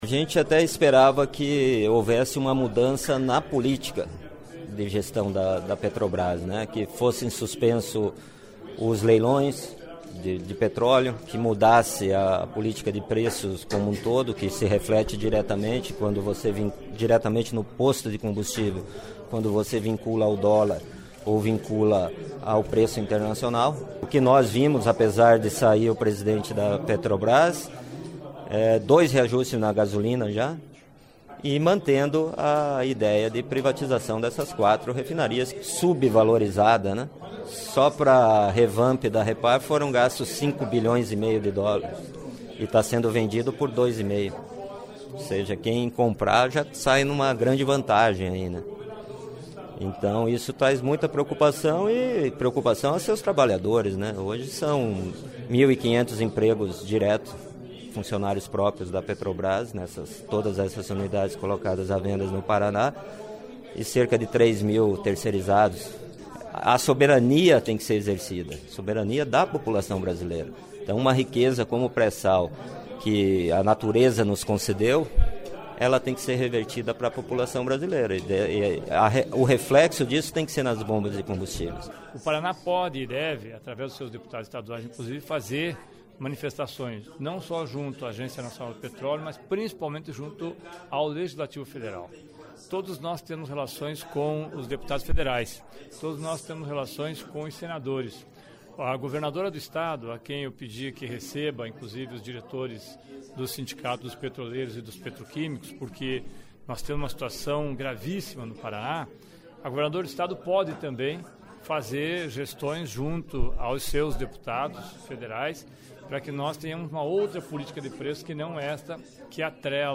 (sonoras)